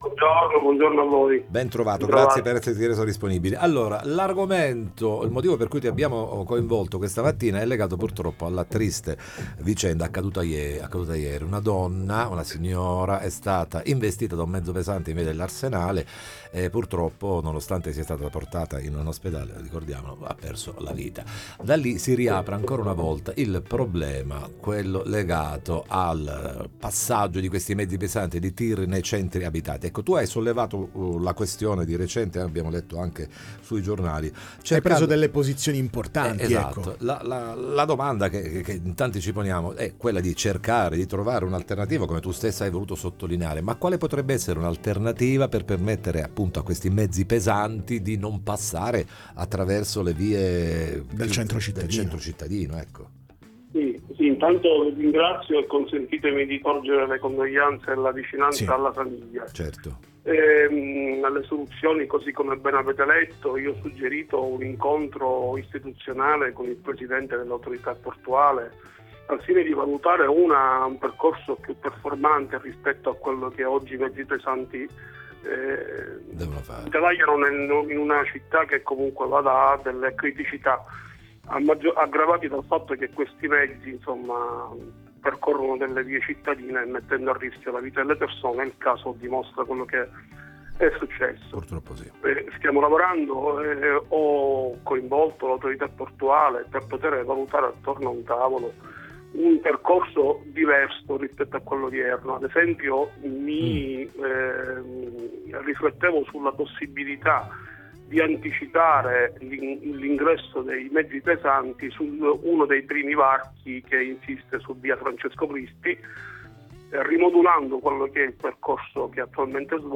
Travolta e trascinata da un Tir in via dell’Arsenale, donna di 46 anni muore in un incidente, ne parliamo con il cons. di Circoscrizione Alessandro Benincasa
Interviste